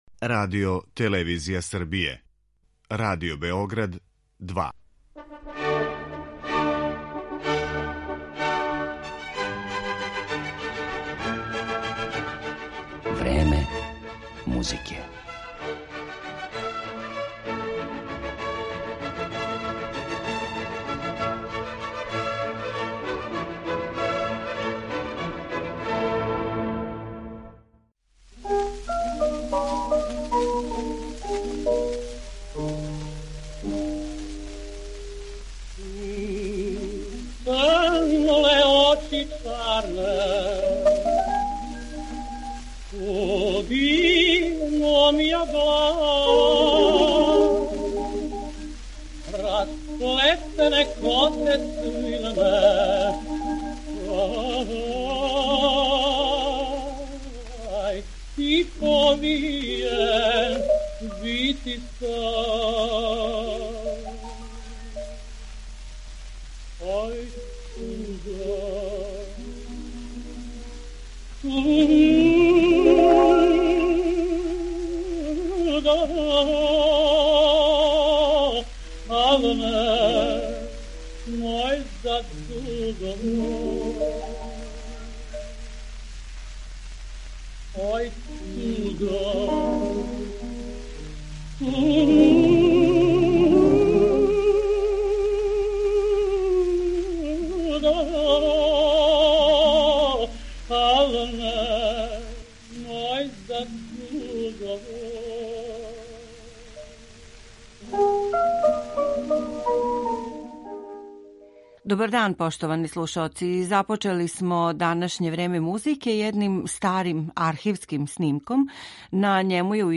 Његов опус ћемо, осим поменутим делима, представити и хорским и оркестарским композицијама, као и соло песмама.